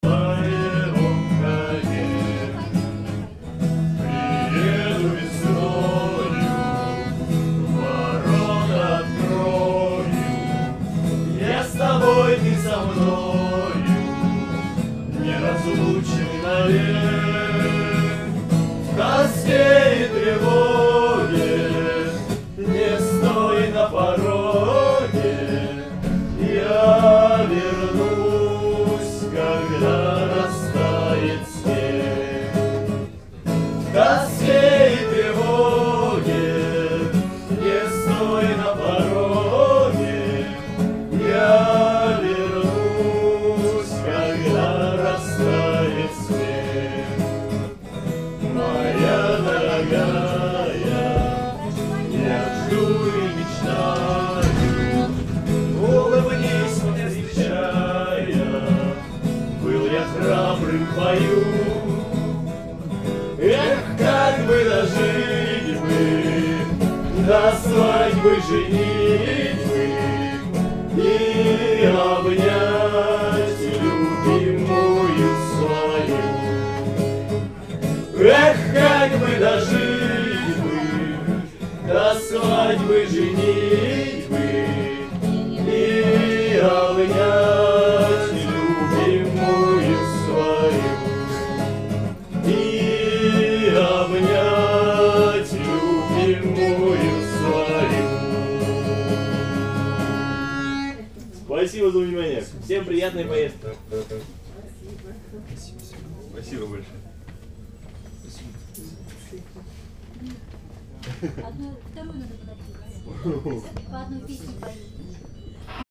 musik-i-tag.mp3